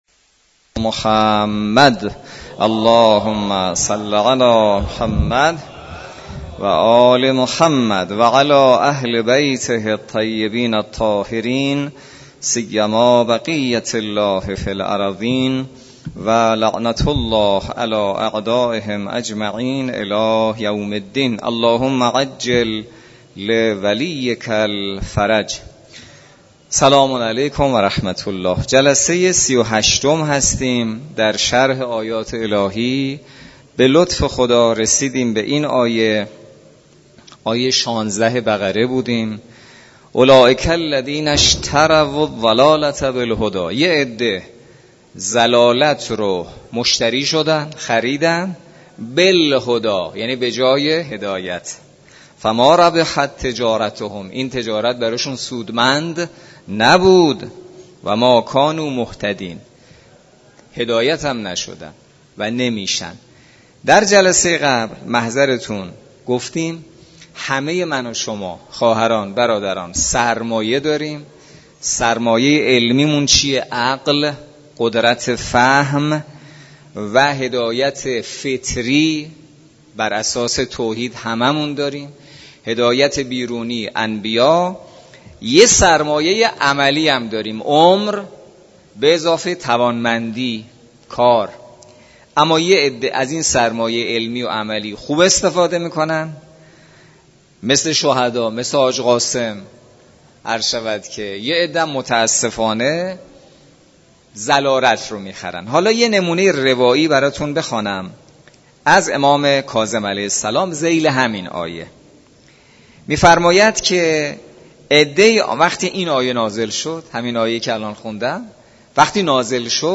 برگزاری بیست و یکمین جلسه تفسیر سوره مبارکه بقره توسط امام جمعه کاشان در مسجد دانشگاه.
سخنرانی